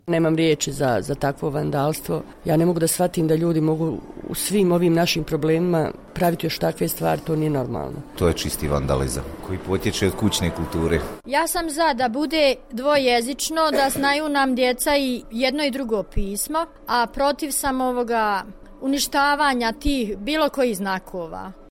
U Kantonu 10, u kojem se najčešće uništavaju ćirilični natpisi, građani osuđuju takav čin: